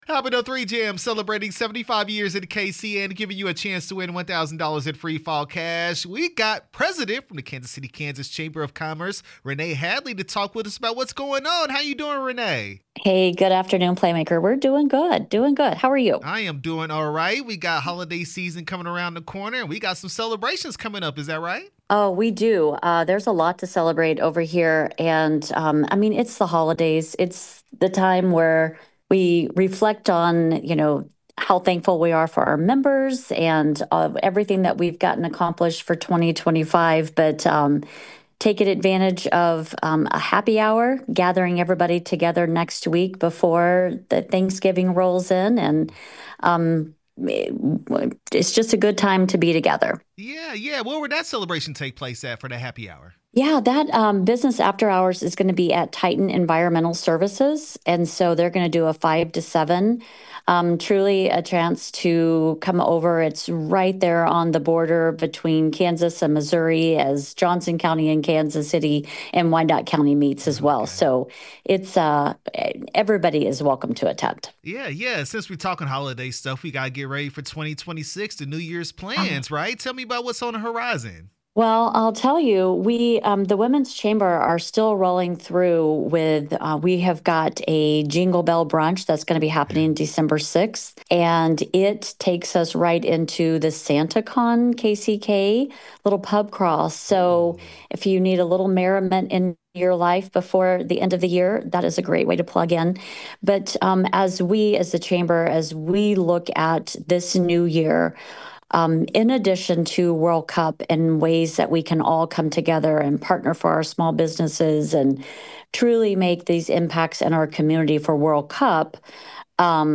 KCK Chamber Of Commerce interview 11/13/25